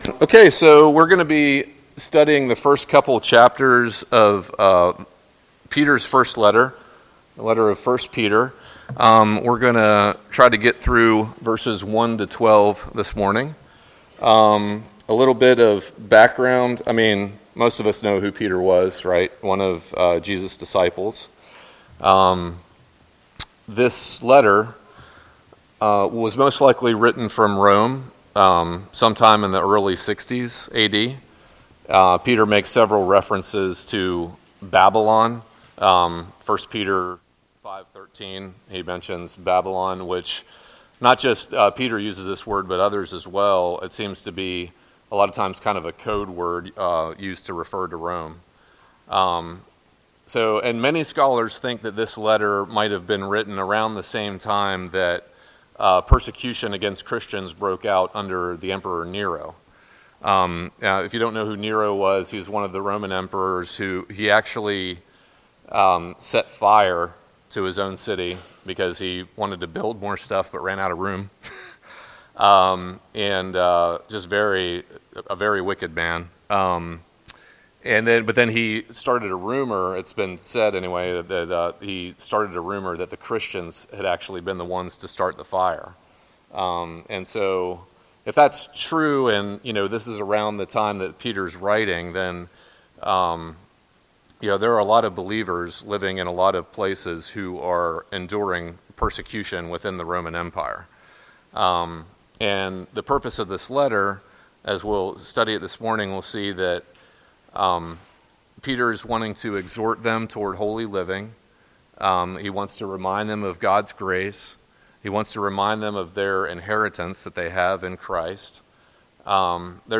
Sermons | James River Community Church